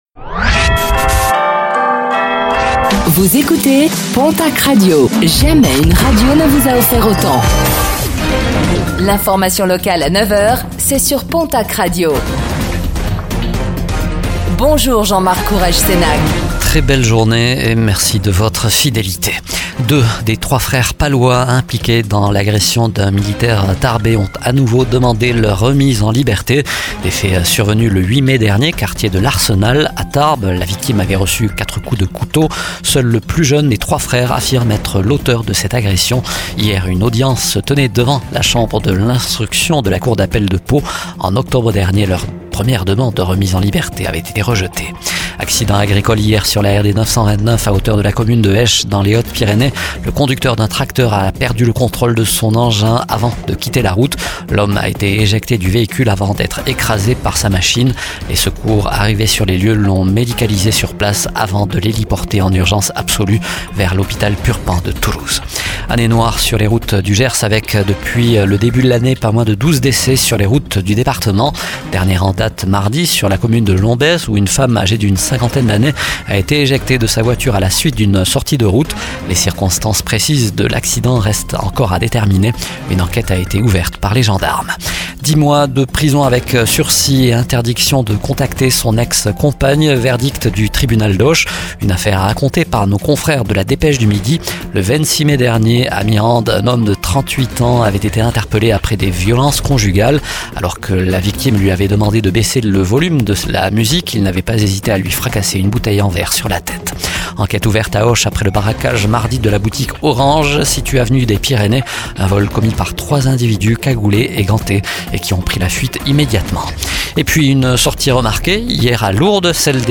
Infos | Jeudi 14 novembre 2024